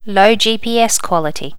low gps quality.wav